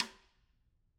Snare2-taps_v3_rr2_Sum.wav